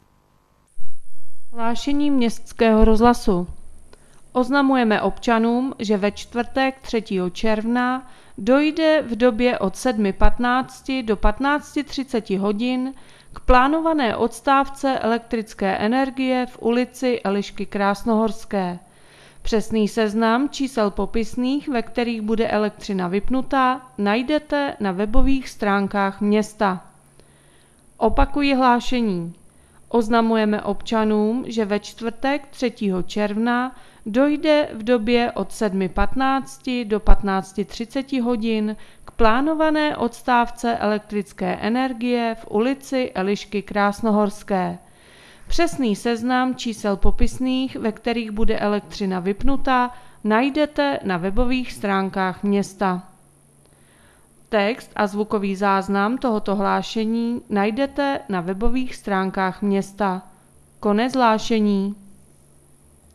Rozhlas | 91. stránka | Město Bělá pod Bezdězem